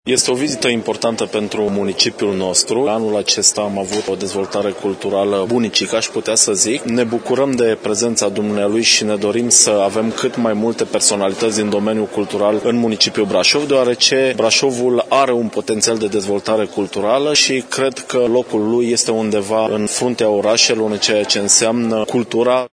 Vizita lui Armand Assante este foarte benefică pentru viața culturală a Brașovului, a arătat viceprimarul Costel Mihai: